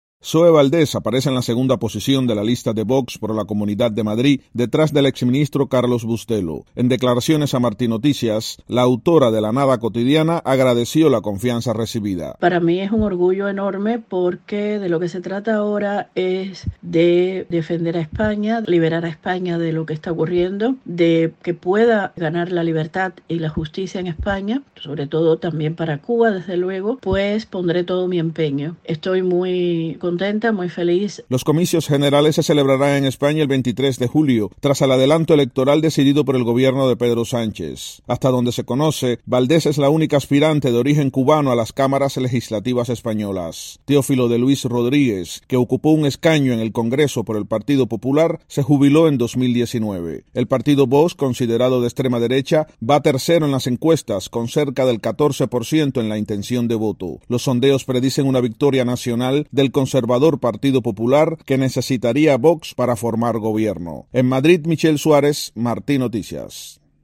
Zoé Valdés en los estudios de Martí Noticias.